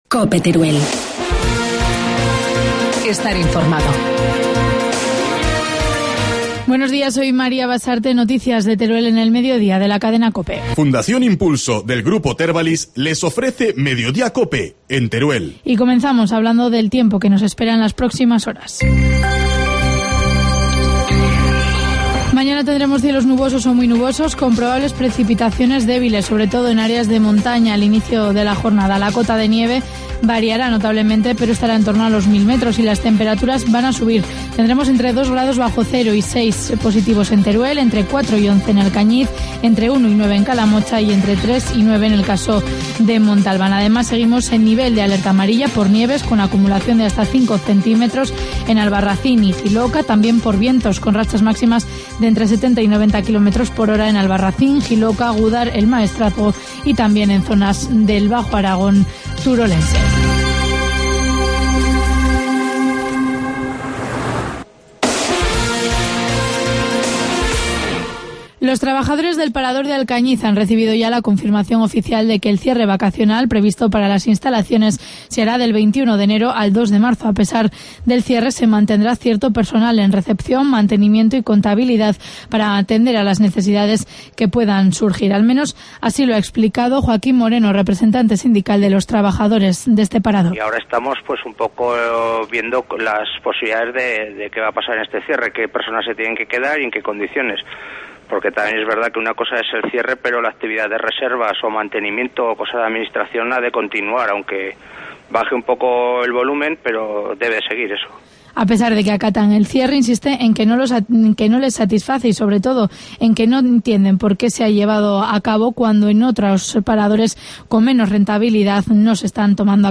Informativo mediodía, miércoles 16 de enero